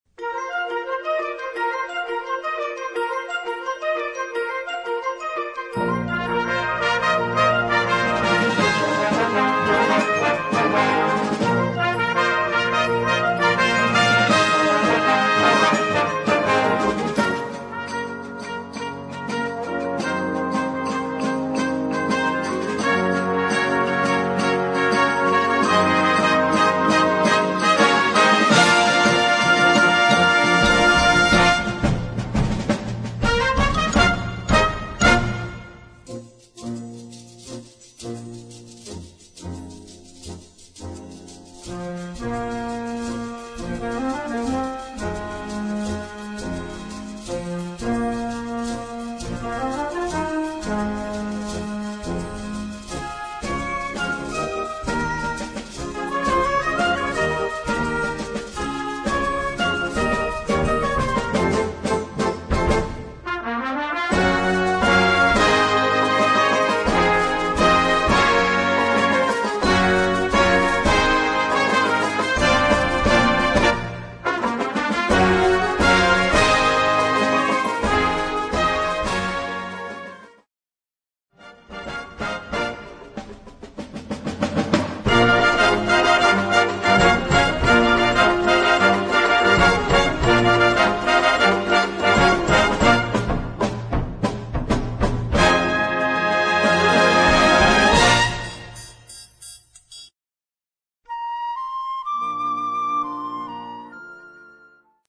High energy, driving tempos, memorable themes, Latin pe